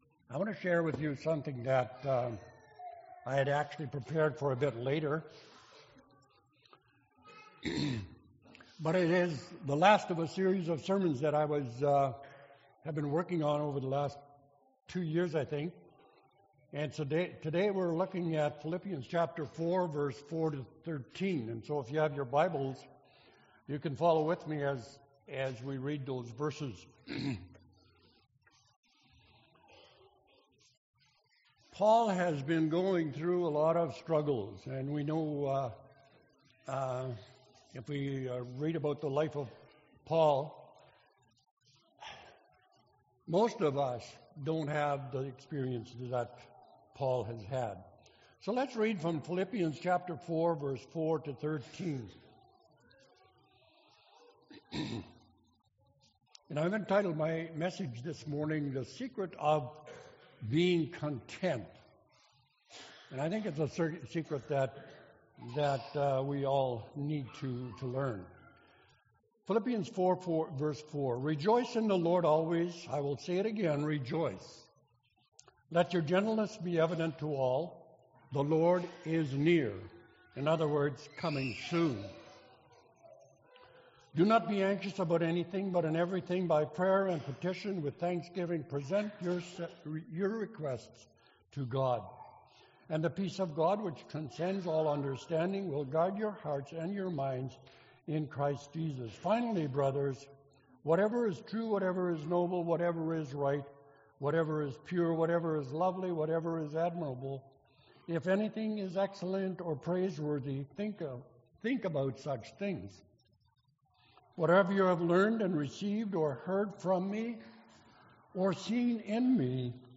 April 3, 2016 – Sermon – Glencross Mennonite Church
April 3, 2016 – Sermon